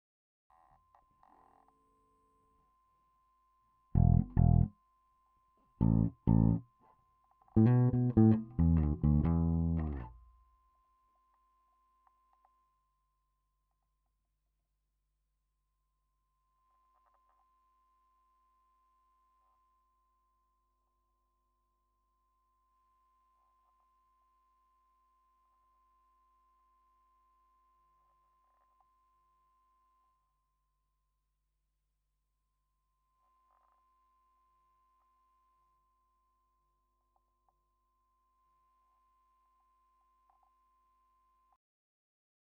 Bass Guitar (PJ Pickup) NOISE!